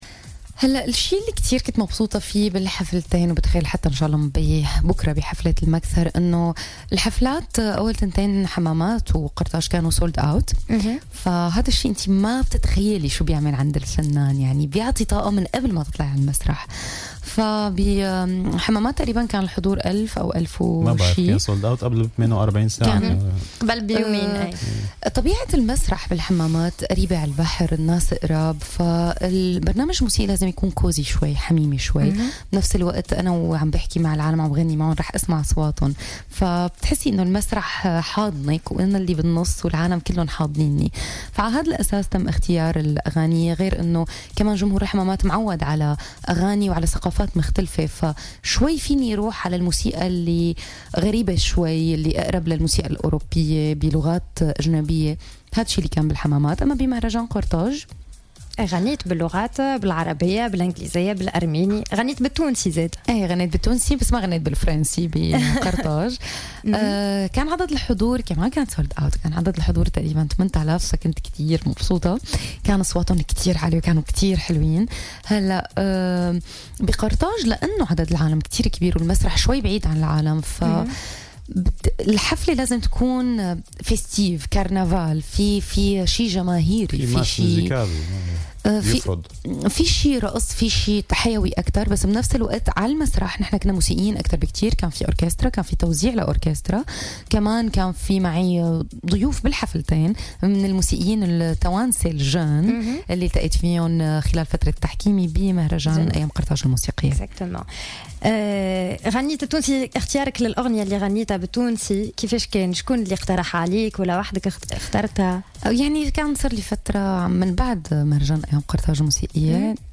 كشفت الفنانة السورية لينا شماميان في مداخلة لها في برنامج "Jawhara By Night" على "الجوهرة أف أم" عن جديدها.